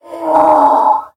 驴：嘶叫
驴拒绝被驯服时
Minecraft_donkey_angry1.mp3